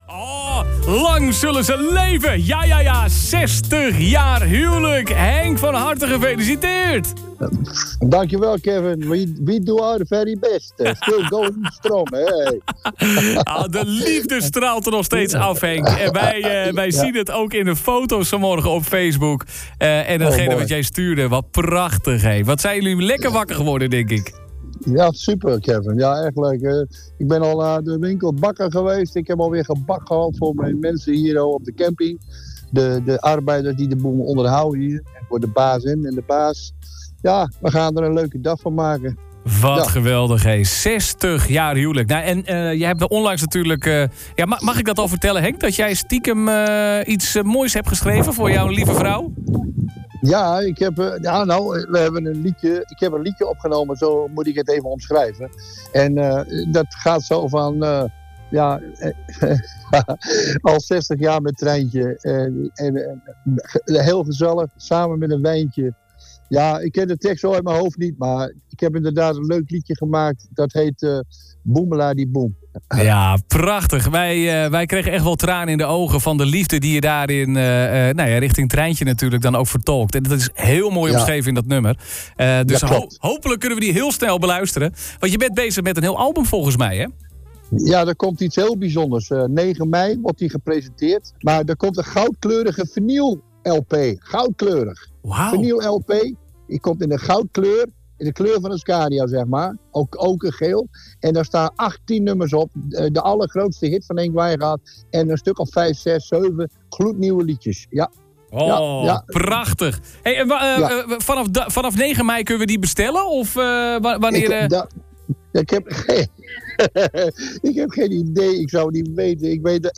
PHONER-HENK-WIJNGAARD-60-JAAR-HUWELIJK.mp3